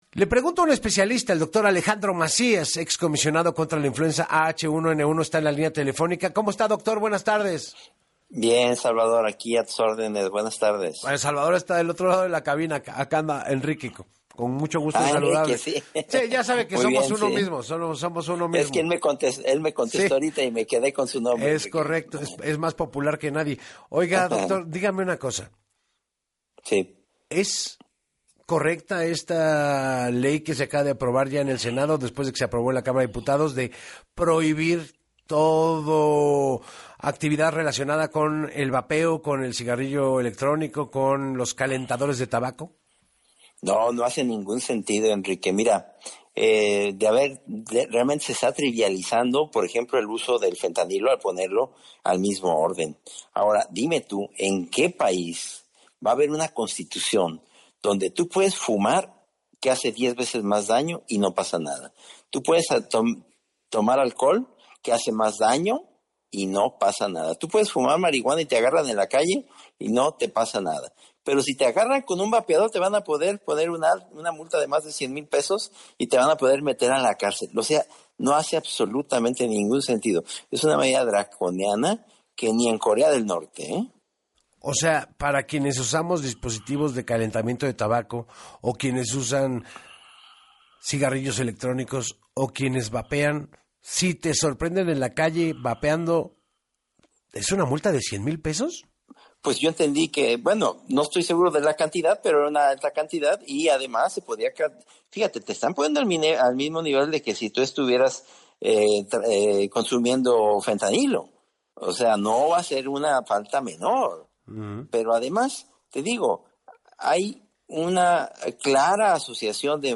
(Que) las ventas del Estado se las quede el crimen organizado o el crimen desorganizado que quizá sea peor, y finalmente vas a hacer que la gente busque sus elementos para vapear o para calentar tabaco en un mercado informal que te va a ofrecer productos de más alto riesgo”, señaló en entrevista para Así Las Cosas con Enrique Hernández Alcázar.